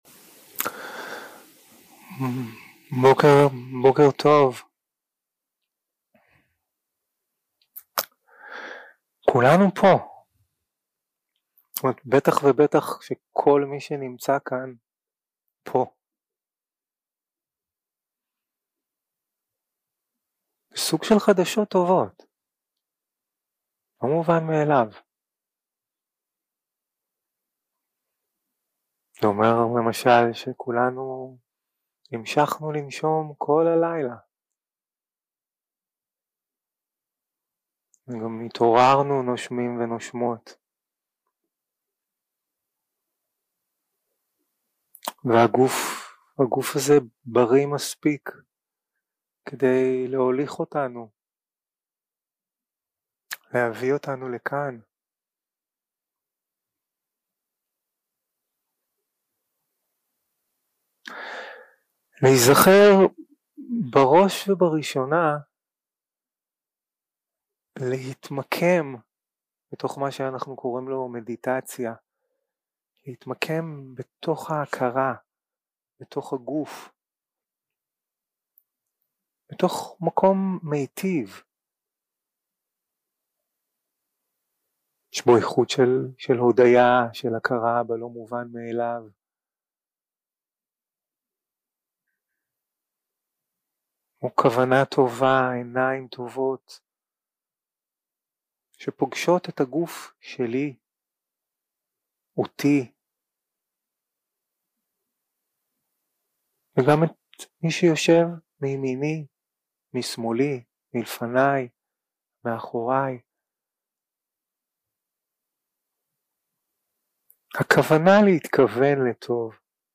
Dharma type: Guided meditation